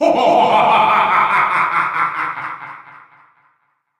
File:Master Hand Laugh (Brawl).oga
Voice clip from Super Smash Bros. Brawl
Master_Hand_Laugh_(Brawl).oga.mp3